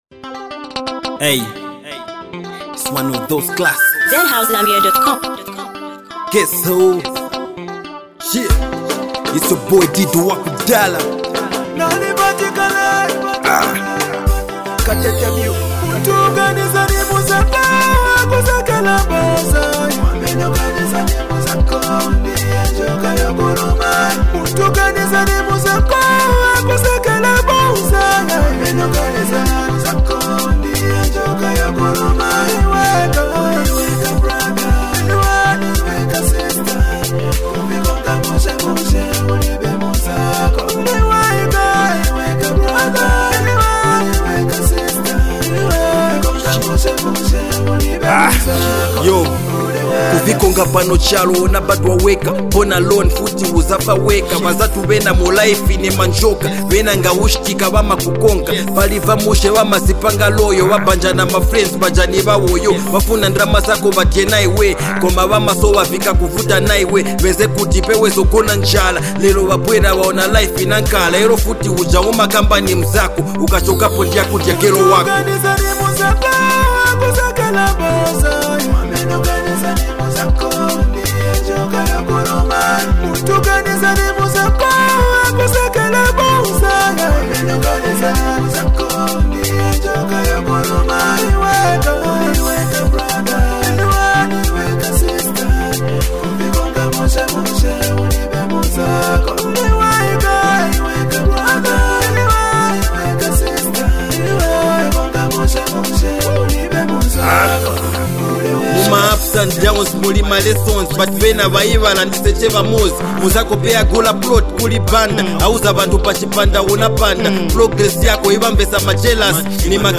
A soulful and emotional anthem that speaks to the heart!